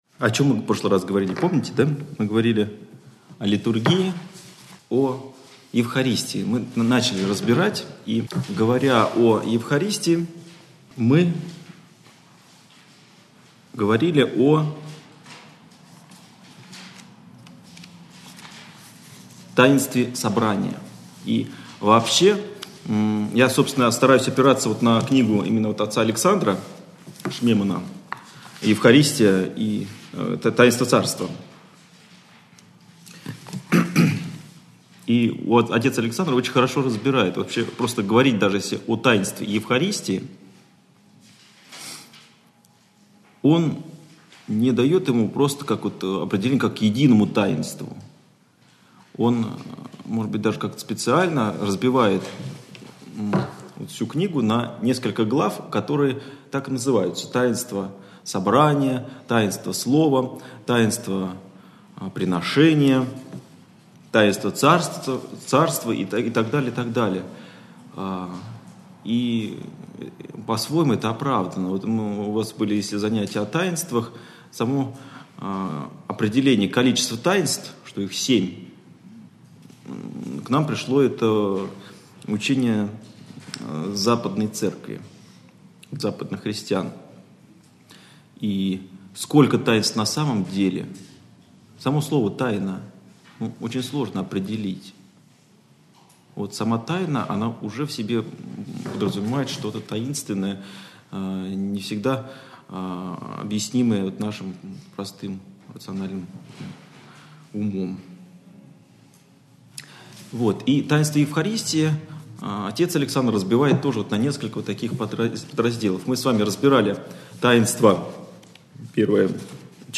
Общедоступный православный лекторий 2013-2014